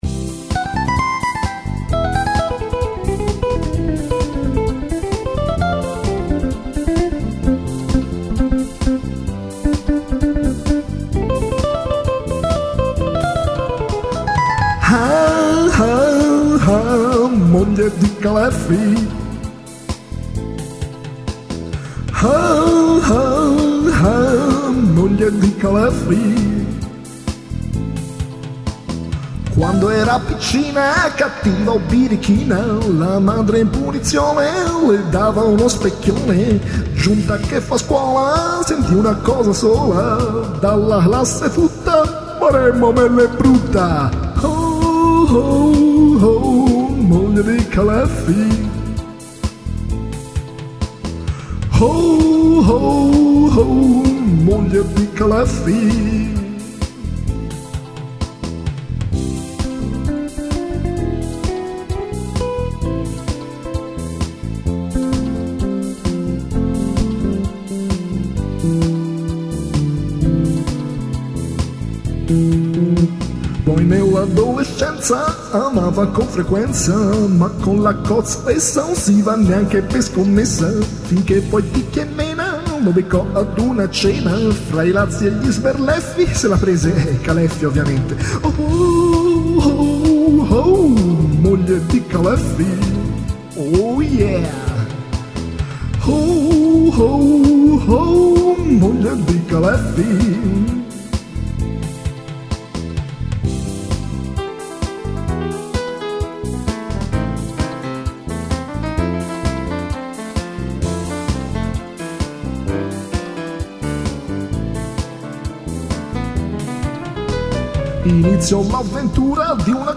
una ballata con chitarra
cantata con ironia buona.